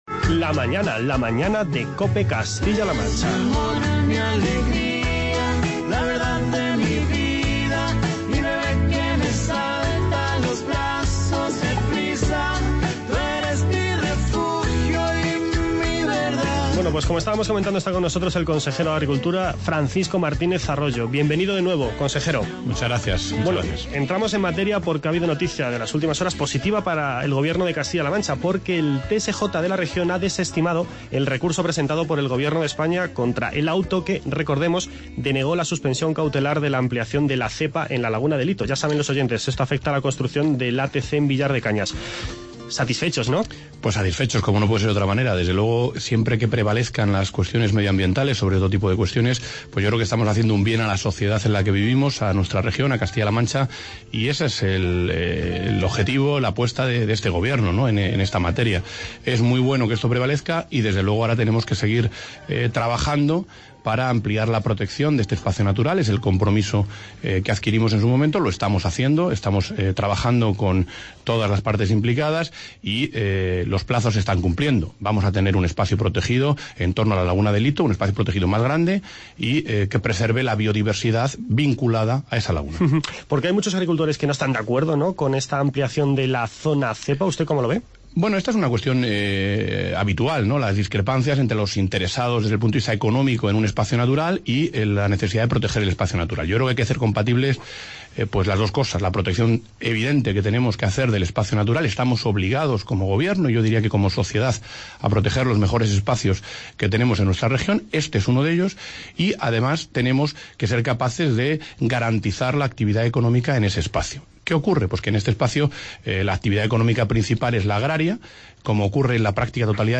Escuche las entrevistas con el consejero de Agricultura